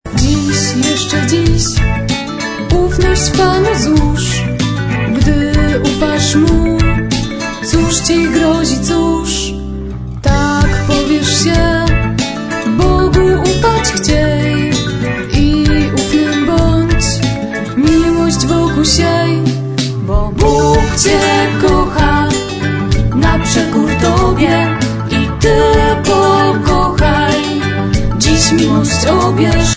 Mocno elektryczna i energetyczna.